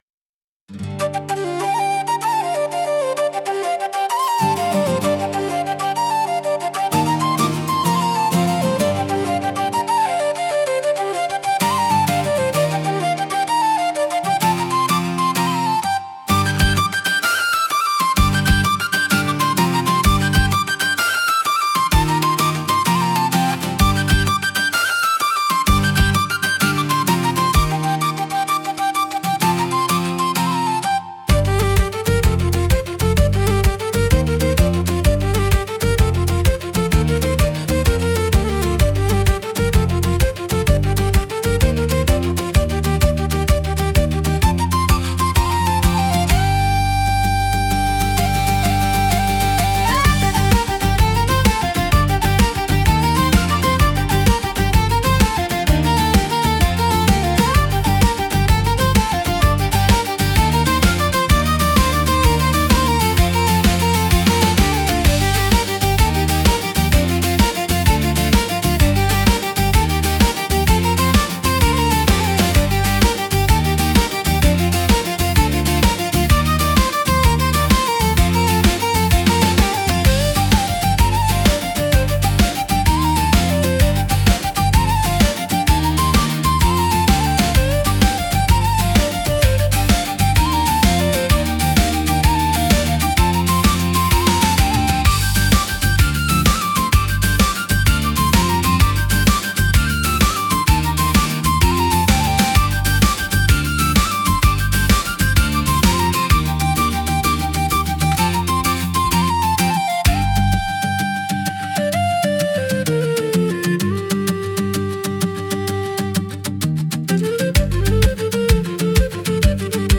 聴く人に親しみやすさと爽やかな感動を届ける民族的で情緒豊かなジャンルです。